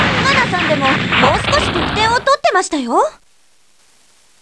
しかし、クリアできないときは得点によってモーリンのコメントが変わる。